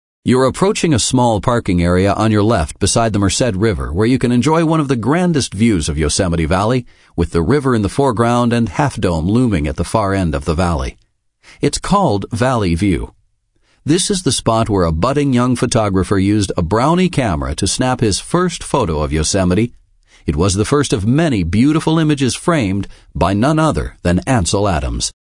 Just Ahead’s GPS-guided smartphone audio tour of Yosemite National Park guides you to and through one of the world’s most remarkable landscapes.